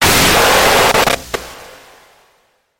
Download Roblox Doors sound effect for free.